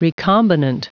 Prononciation du mot recombinant en anglais (fichier audio)
Prononciation du mot : recombinant